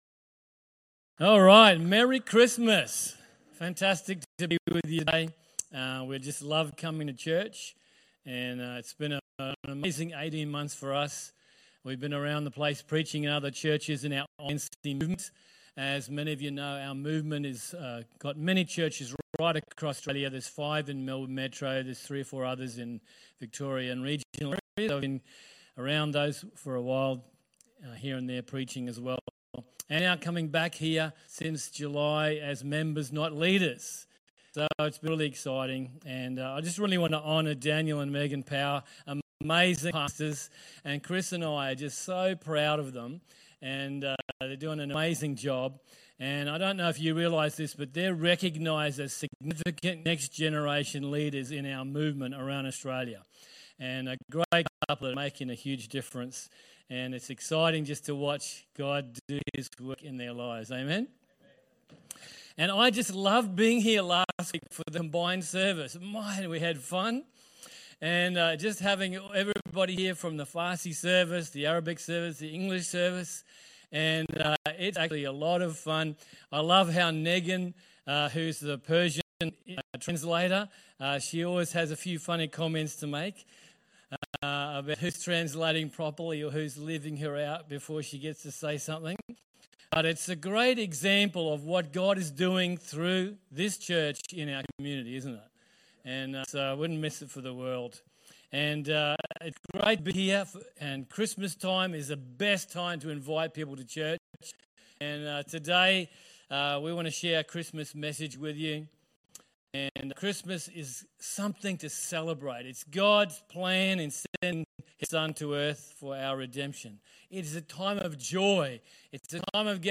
Infinity Church Podcast - English Service | Infinity Church